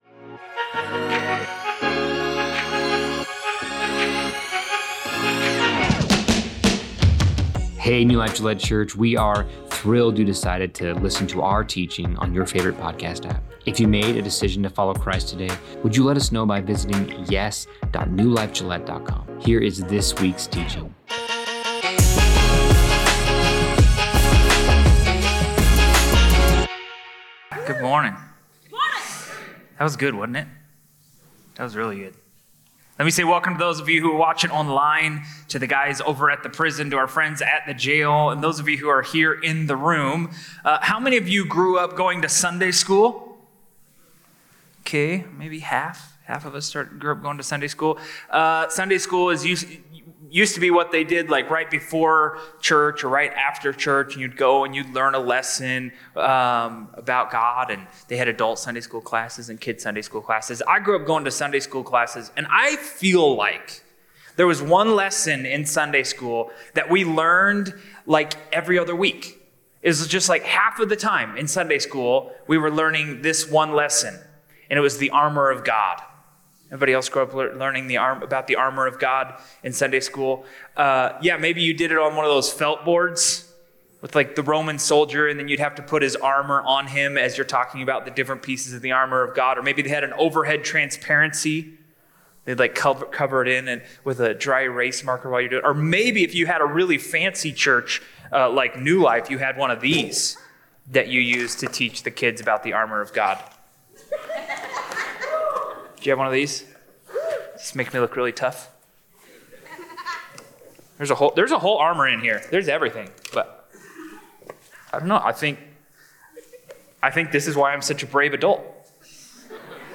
This sermon reminds us that bravery without preparation is recklessness, and preparation without bravery is useless. Together, they empower believers to stand firm in a world full of unseen battles.